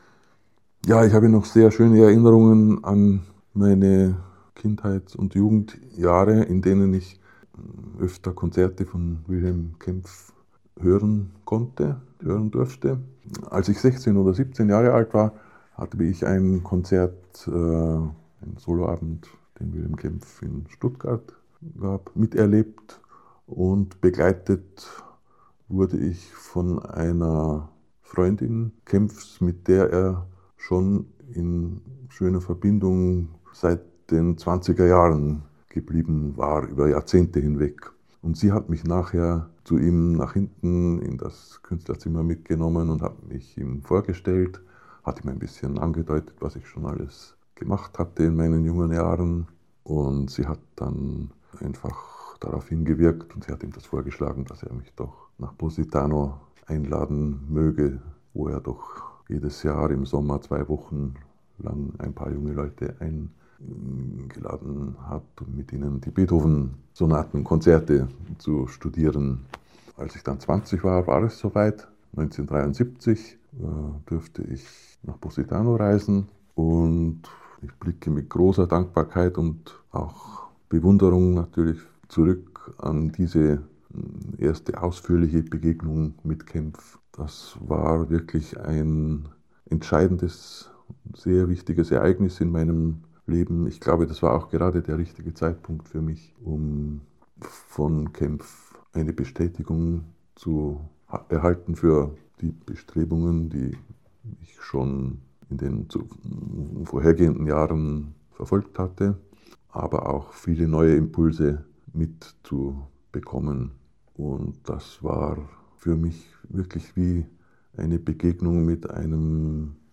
In eight chapters, he reports on the masterclasses at Casa Orfeo and his encounters with Wilhelm Kempff.